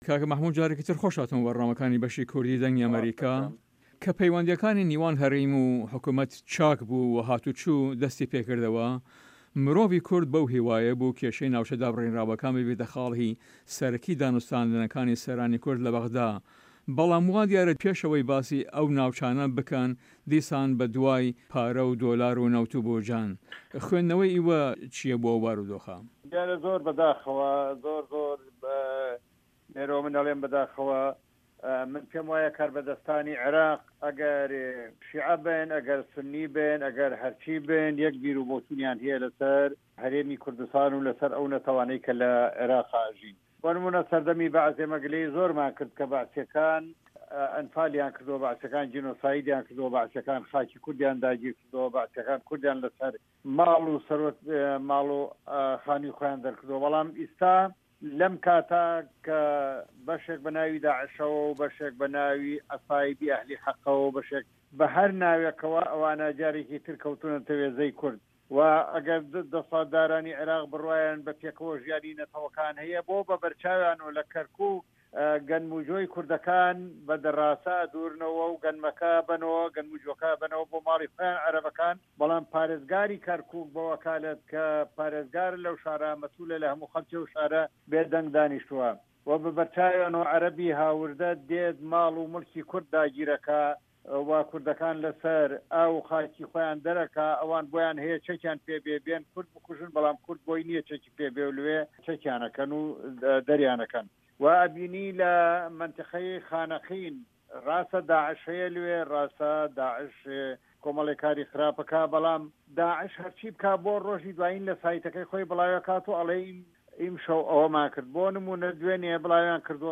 Interview with Mahmoud Sangawi